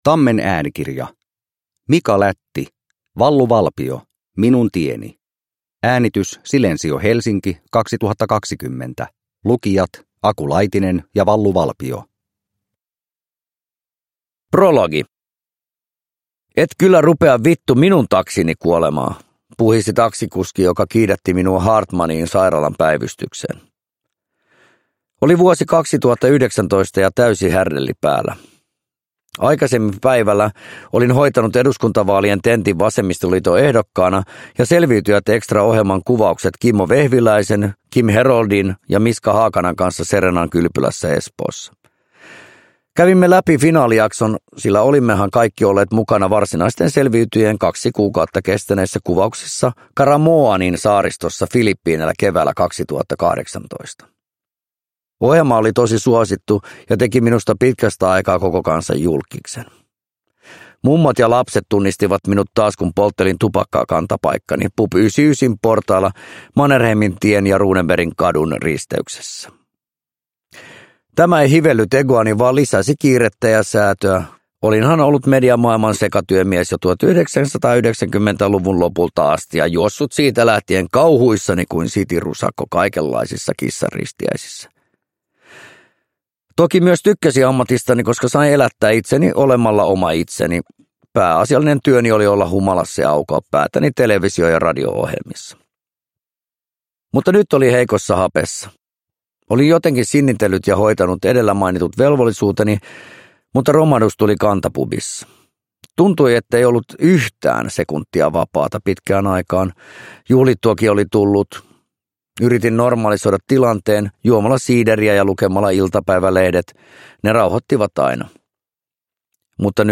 Wallu Valpio – Ljudbok